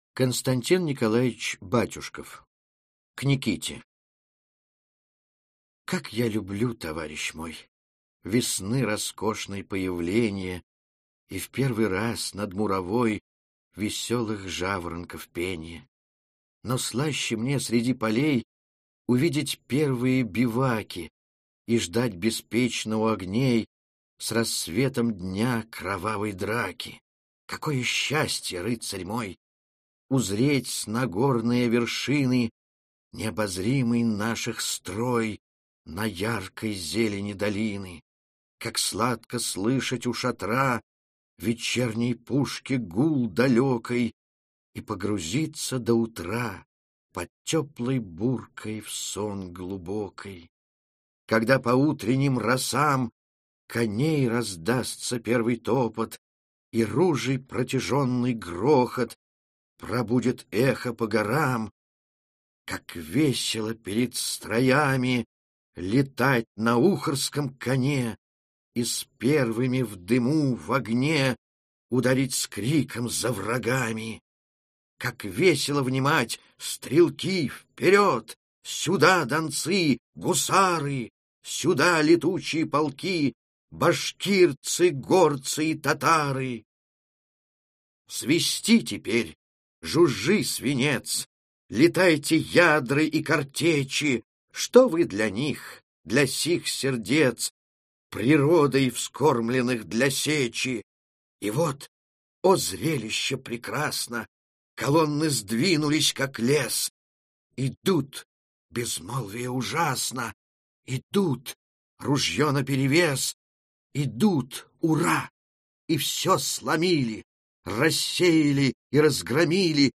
Аудиокнига Хрестоматия по Русской литературе 9-й класс. Часть 1-ая | Библиотека аудиокниг